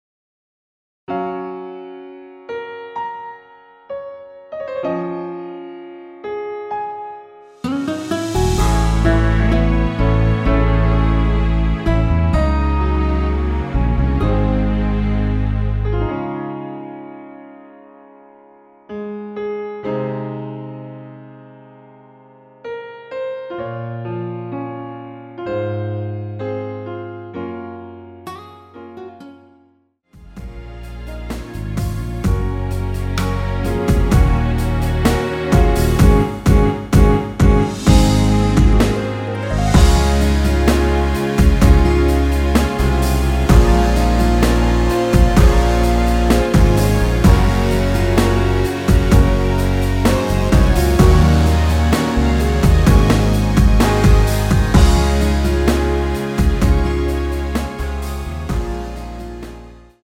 원키에서(-1)내린 MR입니다.
Db
앞부분30초, 뒷부분30초씩 편집해서 올려 드리고 있습니다.
중간에 음이 끈어지고 다시 나오는 이유는
곡명 옆 (-1)은 반음 내림, (+1)은 반음 올림 입니다.